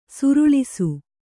♪ suruḷisu